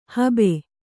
♪ habe